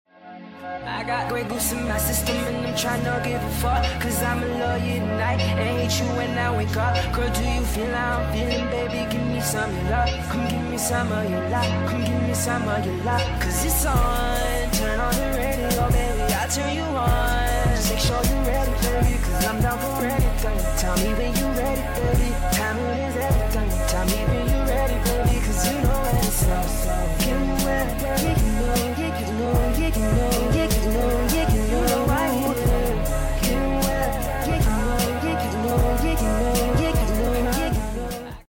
sped up